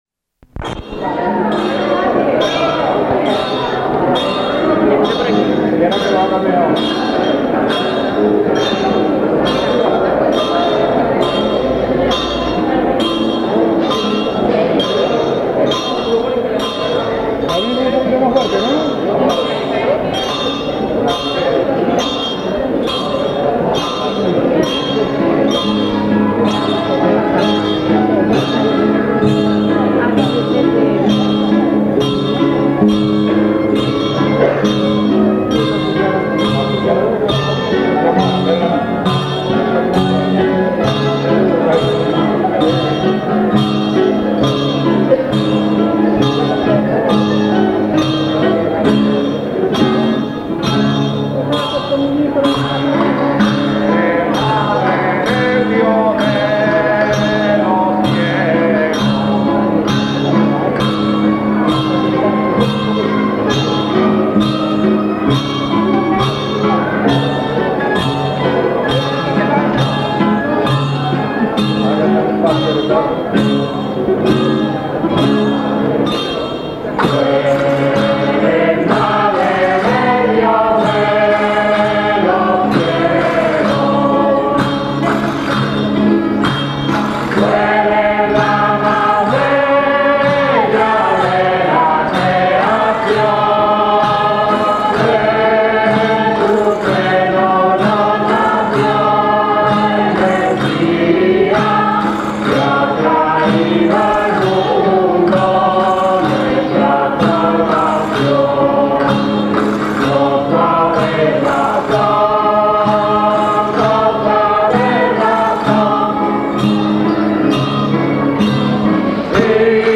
COPLAS DE LOS CAMPANILLEROS DEL VALLE